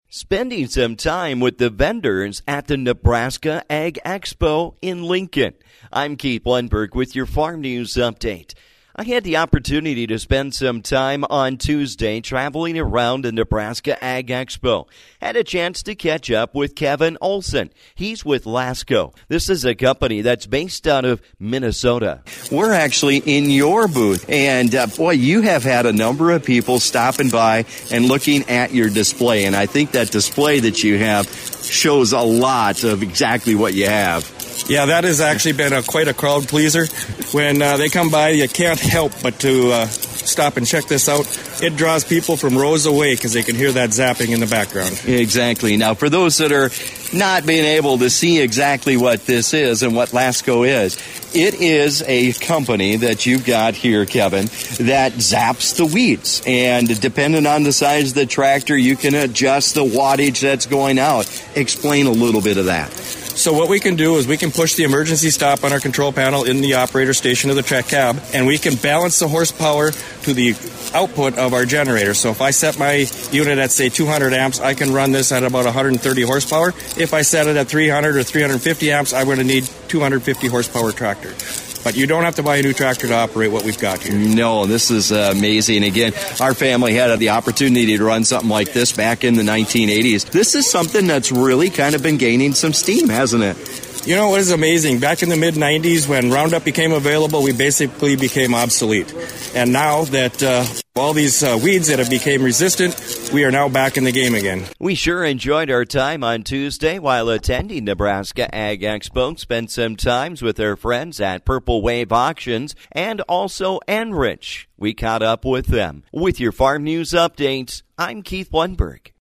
I spent some time walking the Nebraska Ag Expo Trade Show and found a few interesting things.